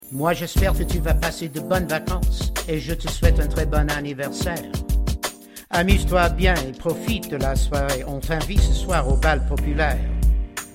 French Raps Song Lyrics and Sound Clip